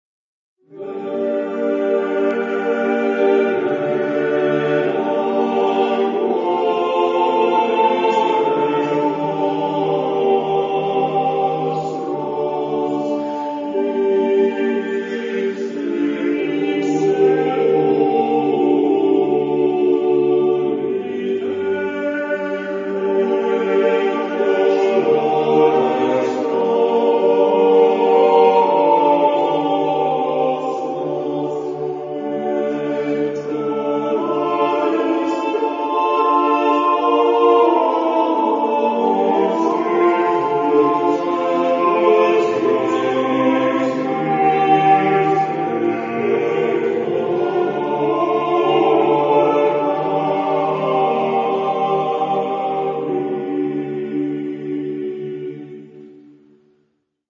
Genre-Style-Form: Renaissance ; Sacred ; Motet
Mood of the piece: sustained ; slow
Type of Choir: SATB  (4 mixed voices )
Tonality: B minor